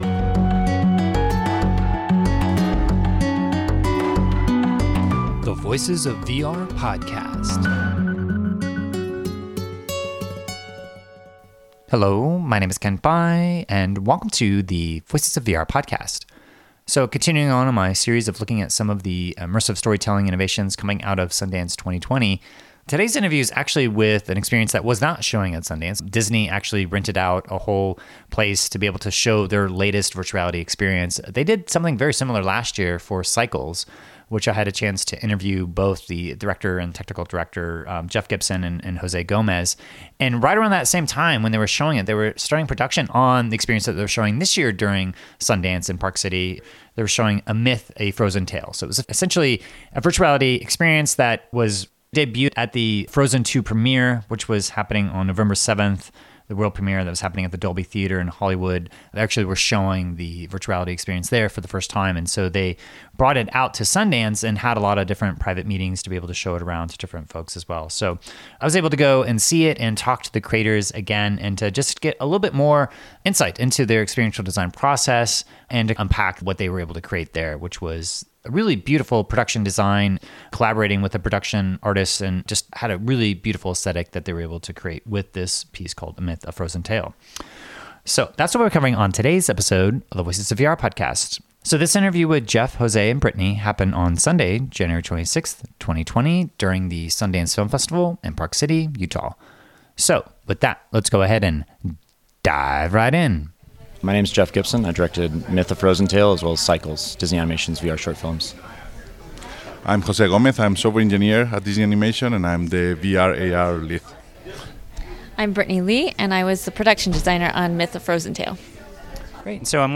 Disney premiered the VR short Myth: A Frozen Tale at the November 7th, 2019 world premiere of Frozen II at the Dolby Theatre in Hollywood, and the creative team came to Park City during Sundance 2020 to privately screen it to industry professionals.